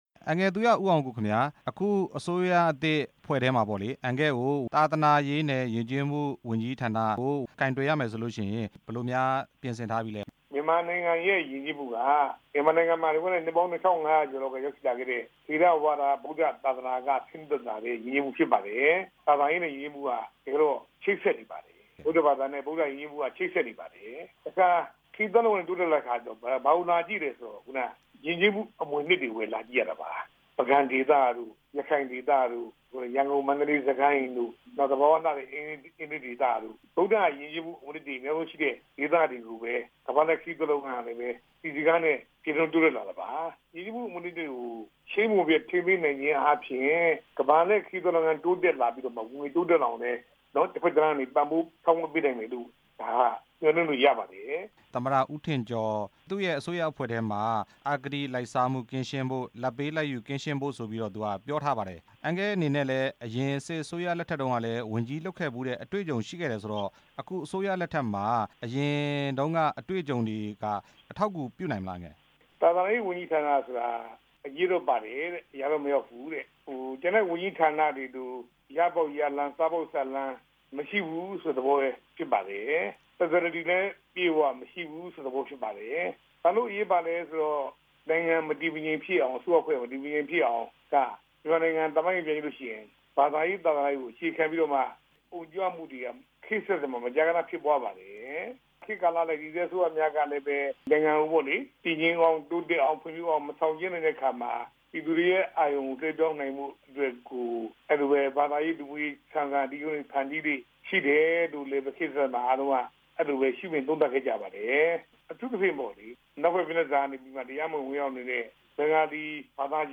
သူရဦးအောင်ကိုနဲ့ မေးမြန်းချက်